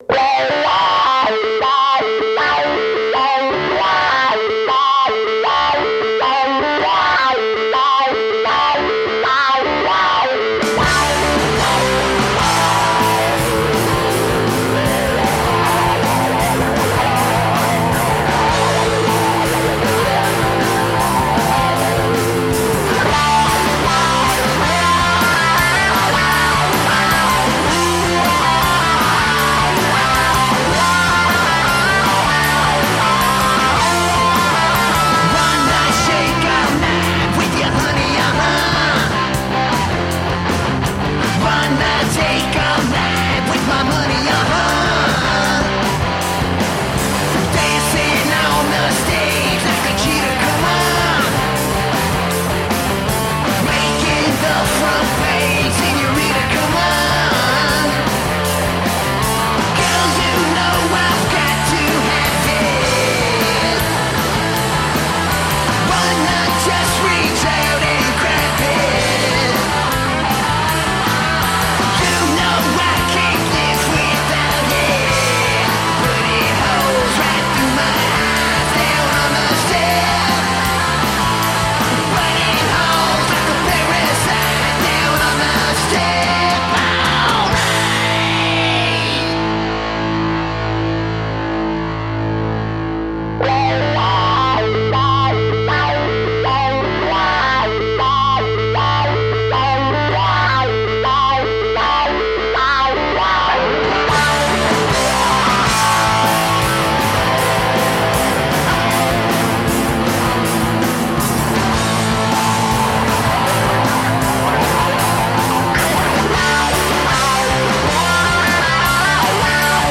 High energy rock and roll.
Snotty punk rock reminiscent of the Sex Pistols.
Tagged as: Hard Rock, Metal, Punk, High Energy Rock and Roll